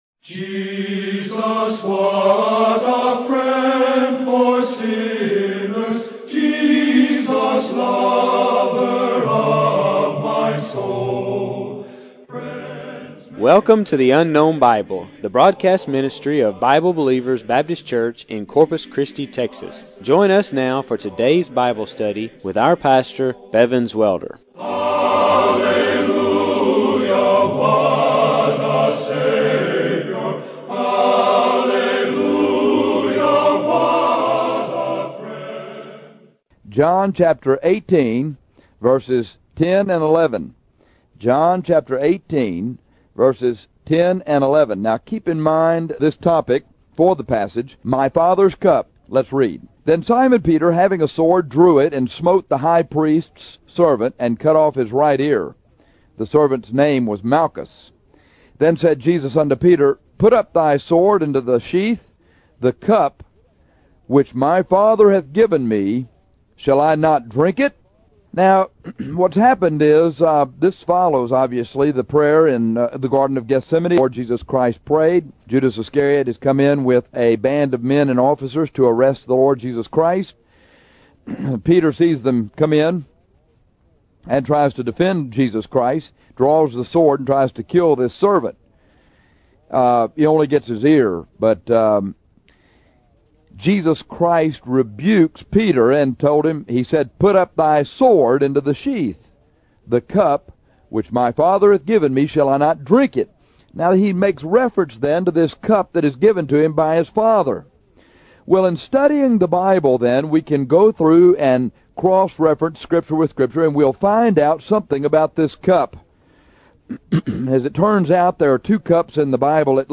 In this lesson called My Fathers Cup, we see that the Father gave Jesus a cup from which to drink that contained His wrath for all of our sins.